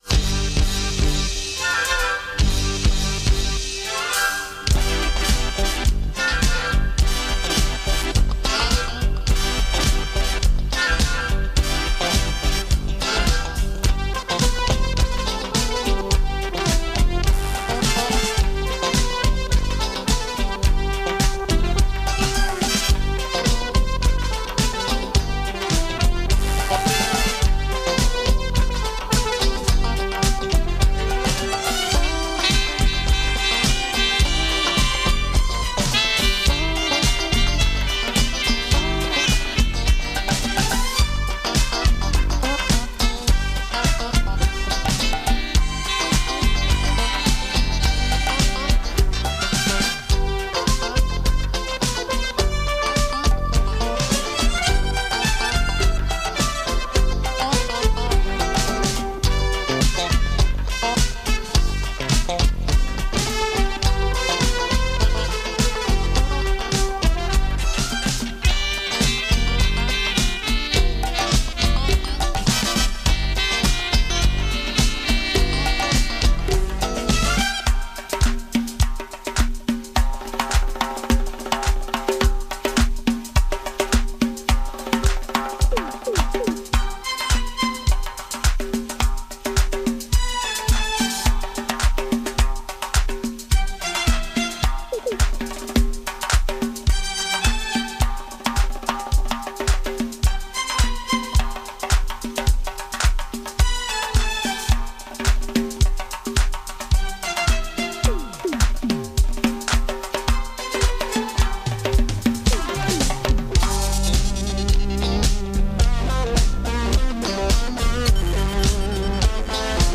эстрадные пьесы
Запись была сделана мною с местного интернет радио.